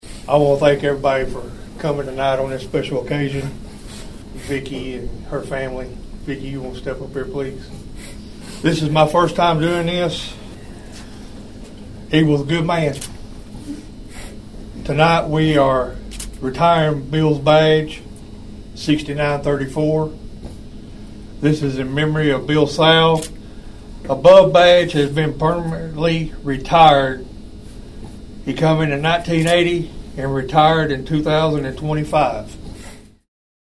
A ceremony was held Monday night to honor a former longtime member of the Obion County Rescue Squad.